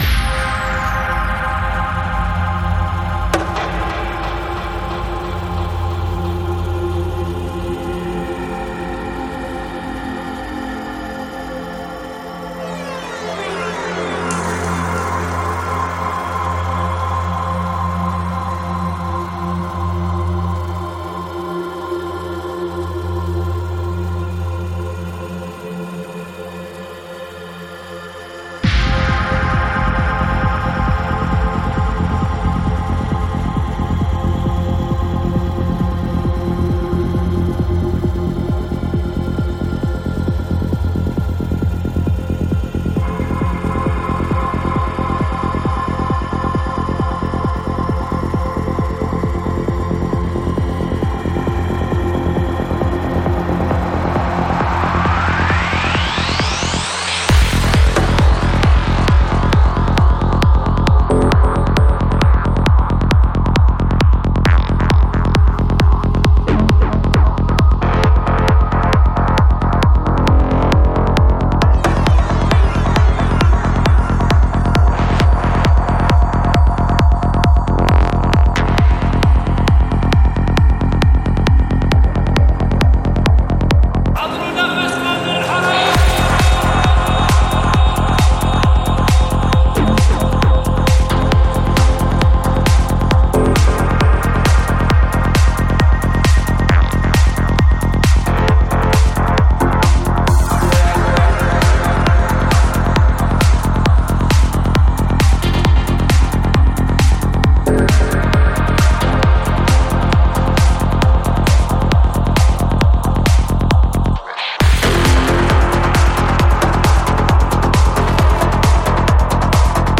Жанр: Psy, Goa Trance